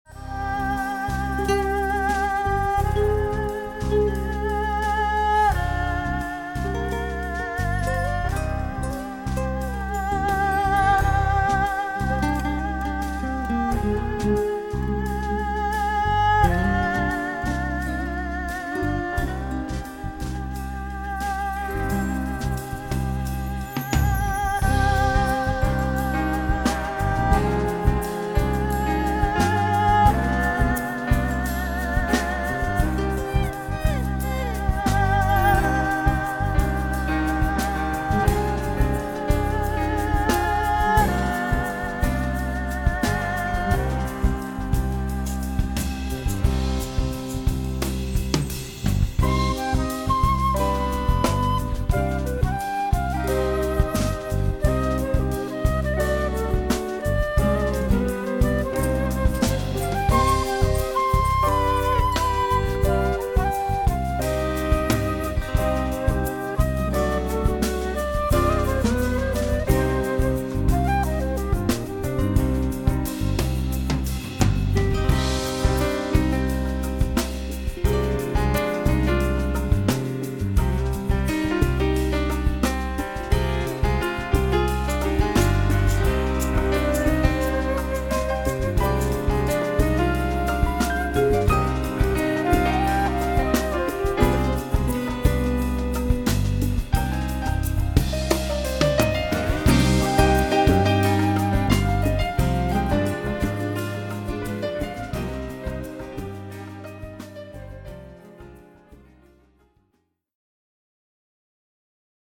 ヒーリングＣＤ